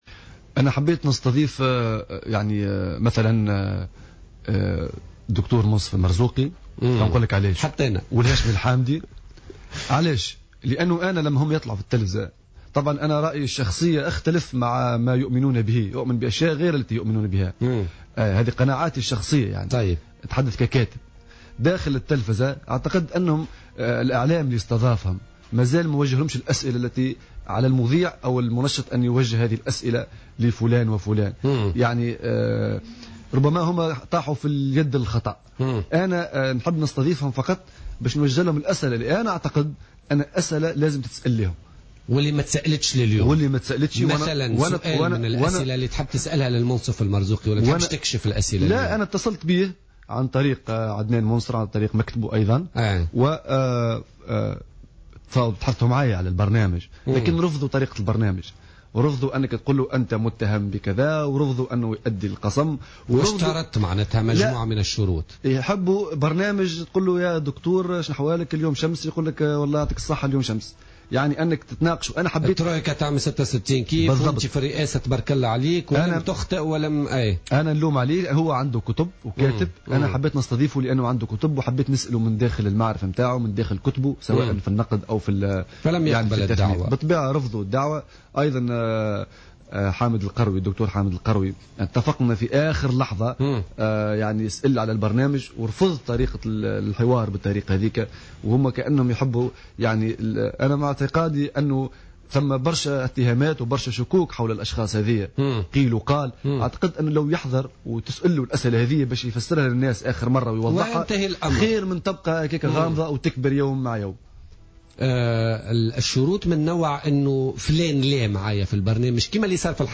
ضيف برنامج "بوليتيكا"